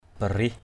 /ba˨˩-rih˨˩/ (d.) nét. stroke. barih akhar br{H aAR nét chữ. pen stroke; handwriting stroke. hu barih h~% br{H có nét. have strokes.
barih.mp3